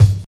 45 KICK.wav